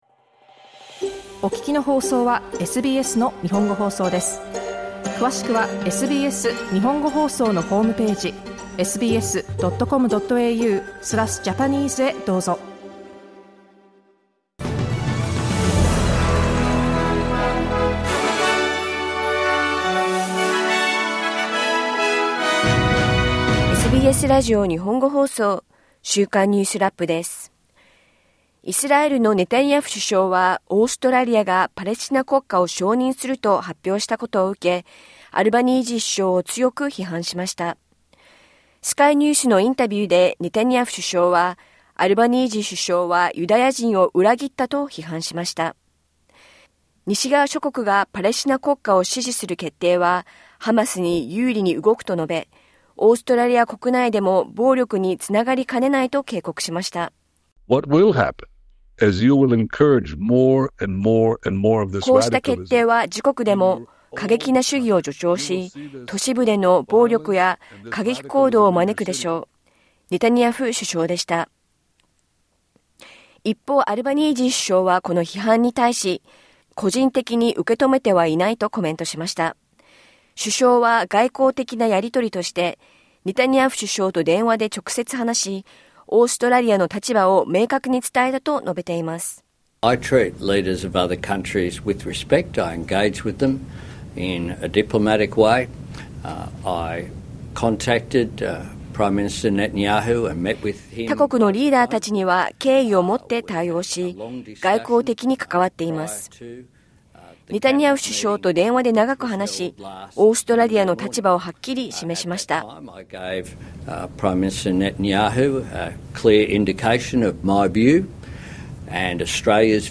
オーストラリアがパレスチナ国家を承認すると発表したことを受け、ネタニヤフ首相がアルバニージー首相を強く批判しました。3日間に渡った経済改革円卓会議が幕を下ろし、政府が今後進める改革の10の方向性が示されました。連邦政府は、オーティズム(自閉症)の人々を、全国障がい者保険制度、NDISから外し、新たなプログラムに移行させることを発表しました。1週間を振り返るニュースラップです。